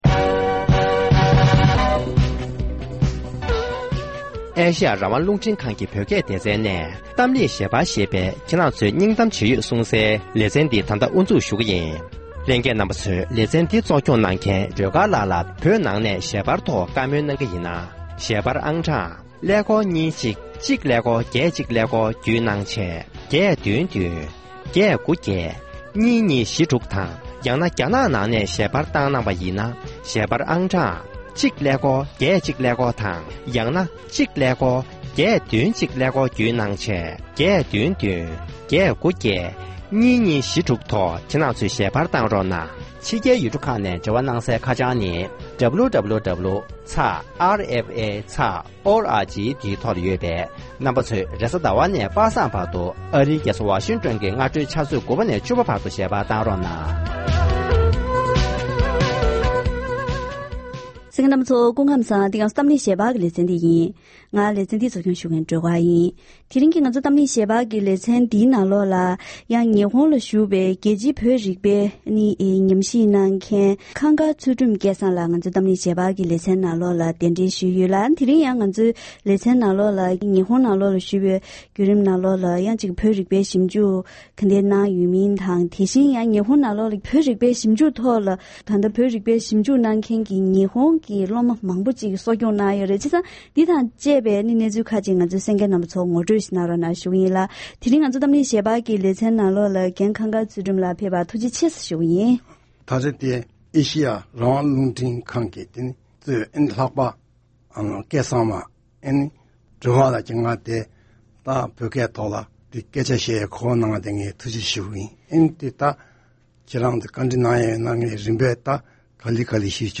ལྷན་གླེང་བ།